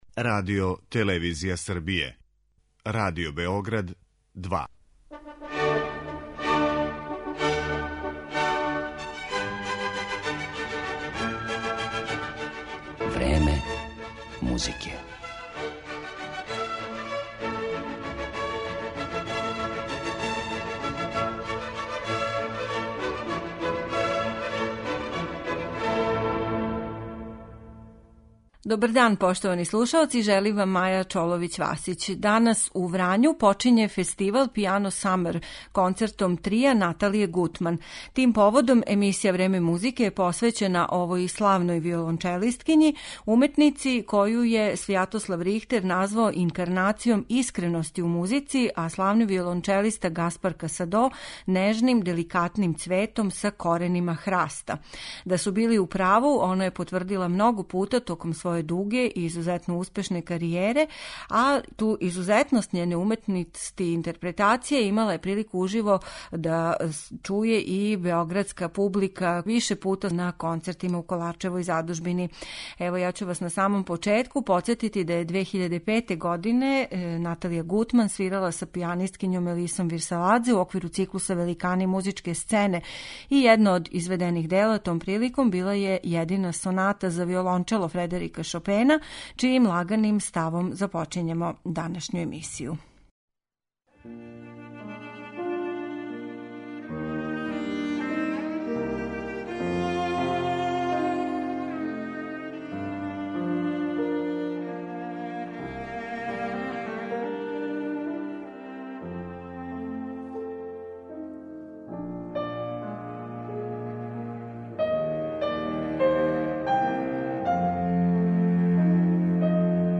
Део њеног богатог извођачког опуса бићете у прилици да чујете у данашњој емисији